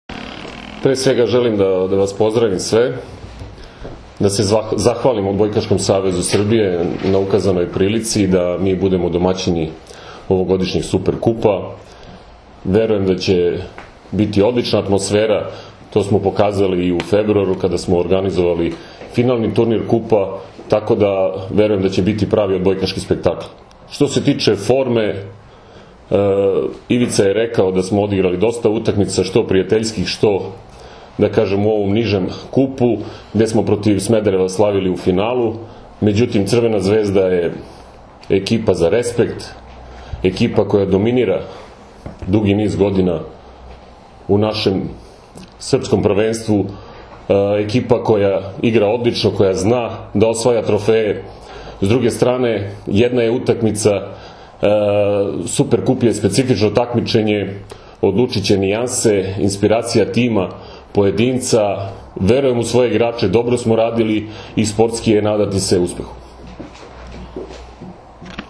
U prostorijama Odbojkaškog saveza Srbije danas je održana konferencija za novinare povodom utakmice VI Super Kupa Srbije 2016, koja će se odigrati sutra (četvrtak, 6. oktobar) od 18,00 časova u dvorani SC “Požarevac” u Požarevcu, između Crvene zvezde i Mladog radnika iz Požarevca, uz direktan prenos na RTS 2.
IZJAVA